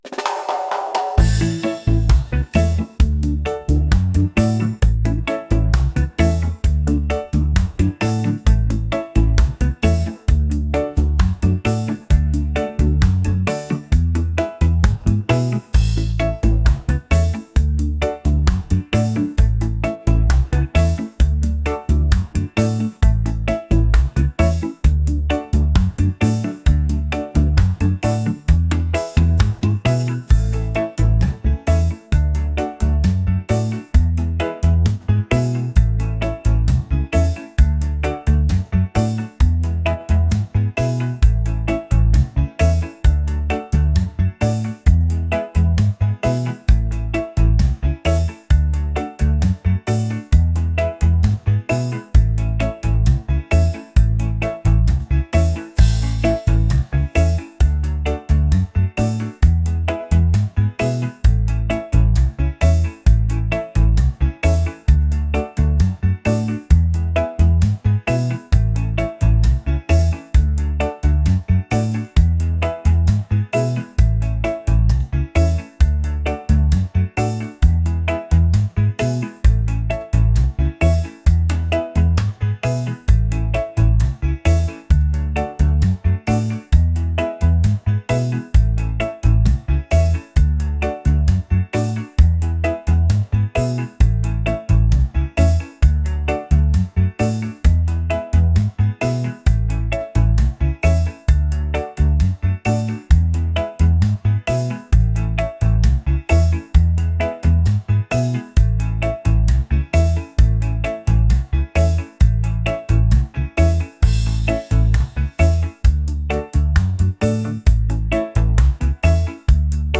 reggae | laid-back | groovy